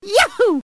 One of Mario's voice clips in Mario Kart DS